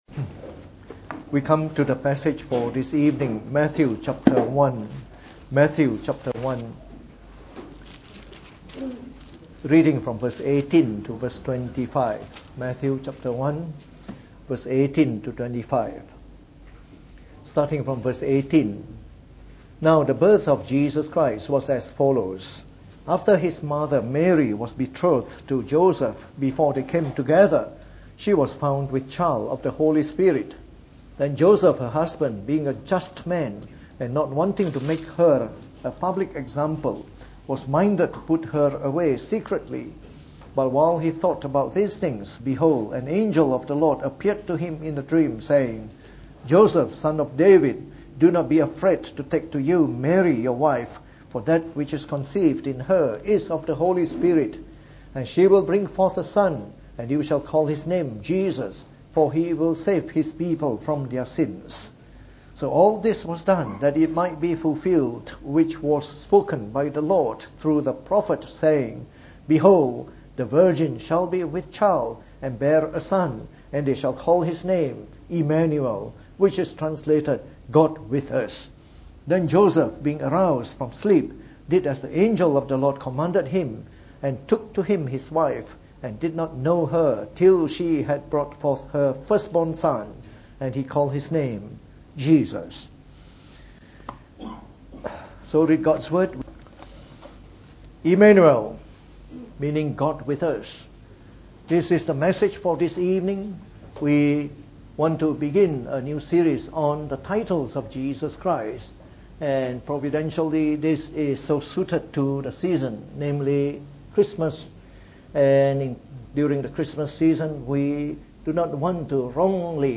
From our new series on the Titles of Jesus Christ delivered in the Evening Service.